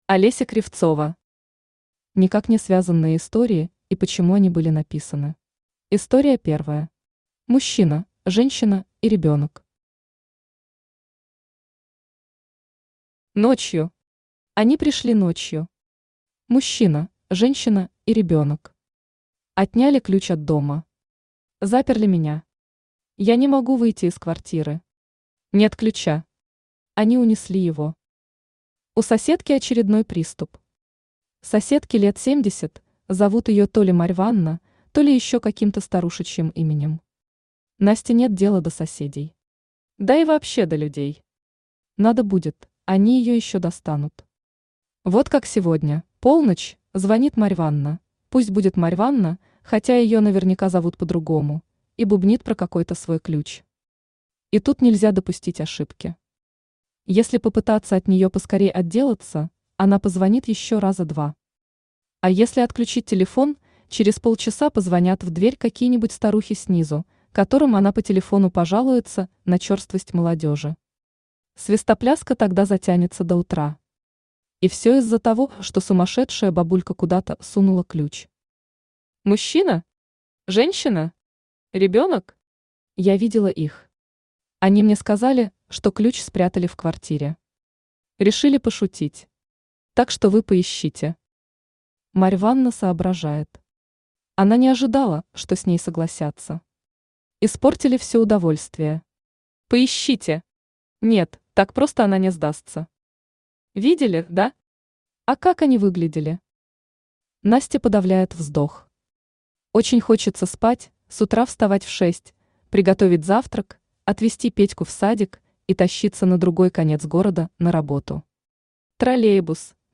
Аудиокнига Никак не связанные истории и почему они были написаны | Библиотека аудиокниг
Читает аудиокнигу Авточтец ЛитРес.